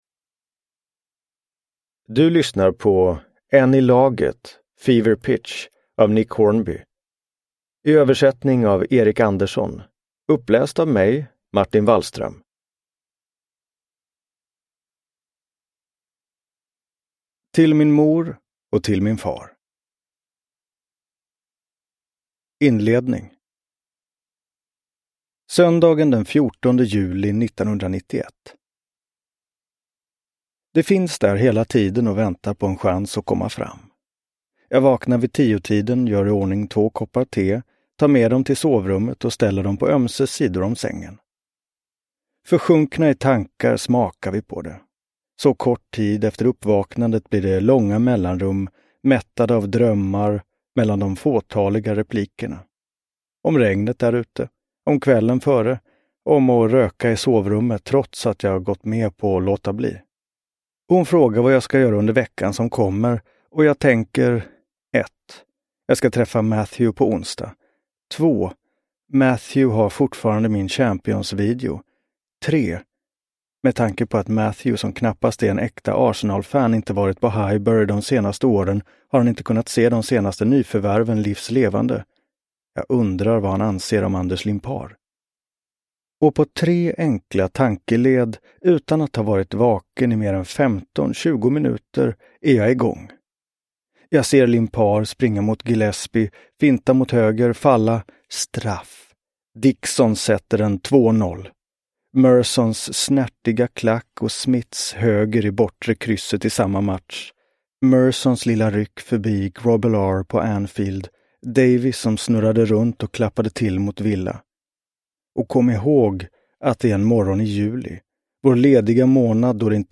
Fever Pitch : en i laget – Ljudbok – Laddas ner
Uppläsare: Martin Wallström